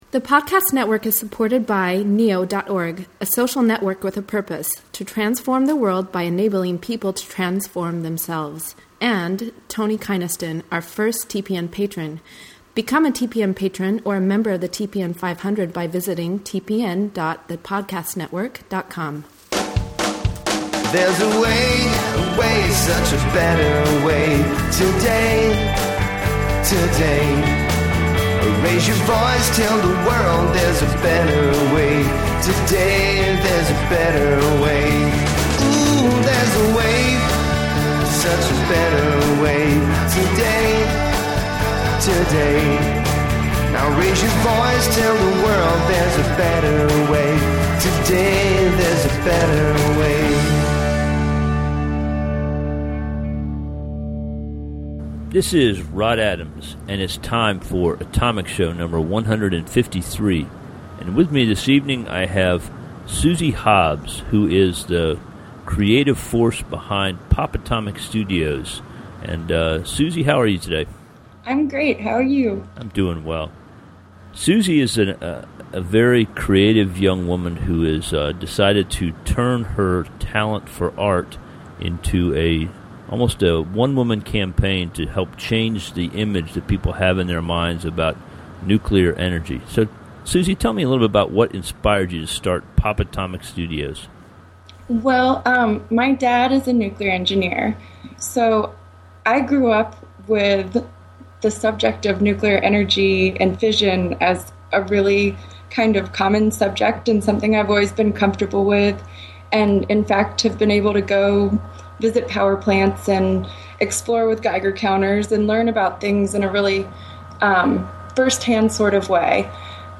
Her enthusiasm is infectious.